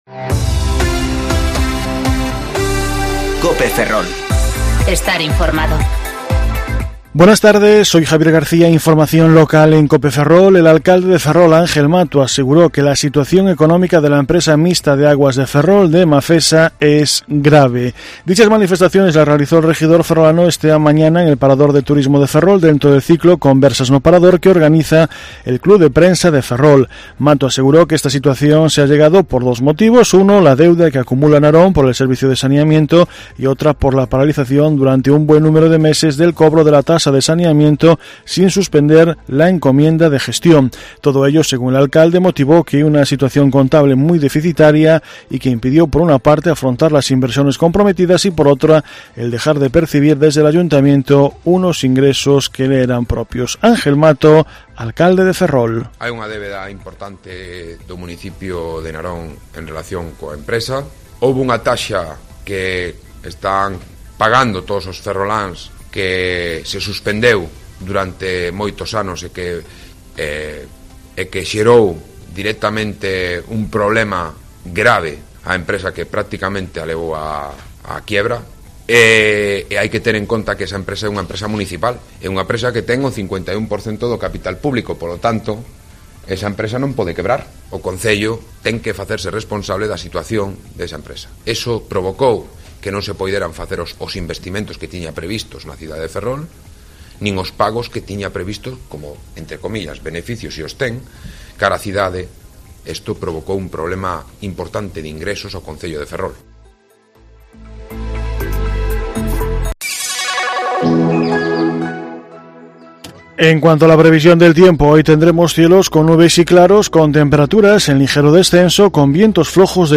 Informativo Mediodía Cope Ferrol 10/09/2019 (De 14.20 a 14.30 horas)